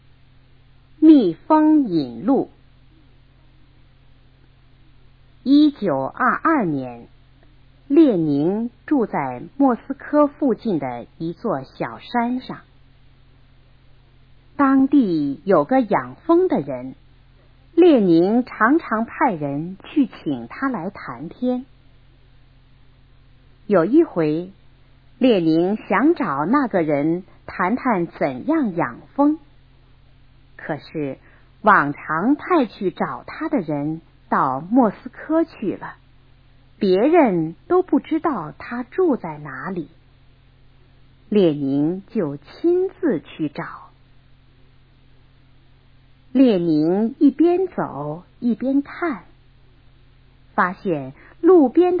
蜜蜂引路 课文朗读